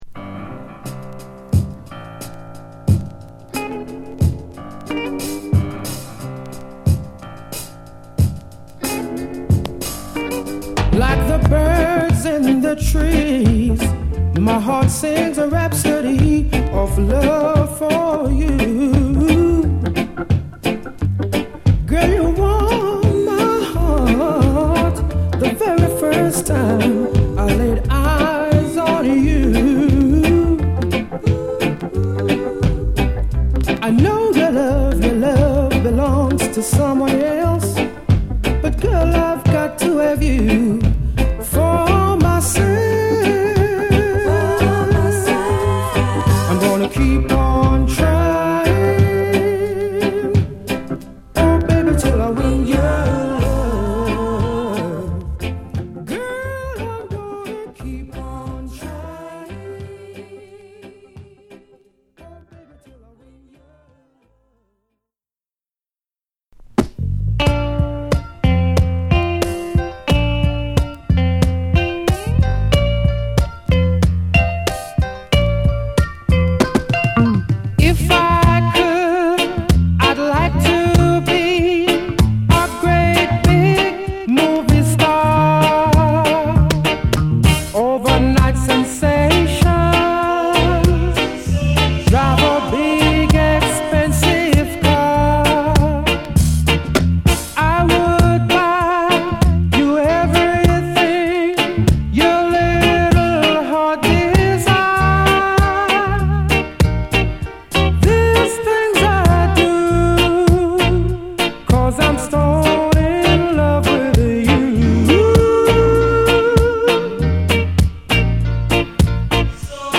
Funky Reggae 傑作アルバム！